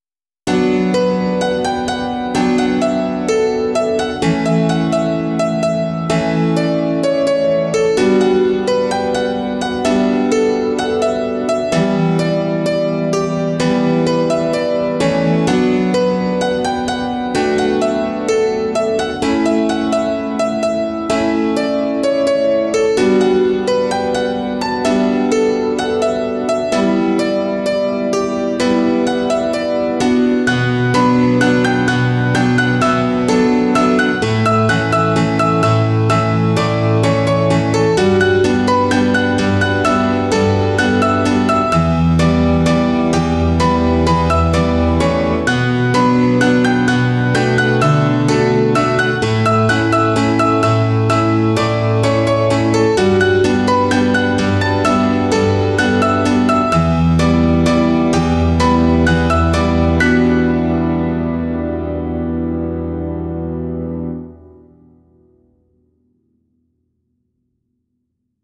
PIANO ABC (33)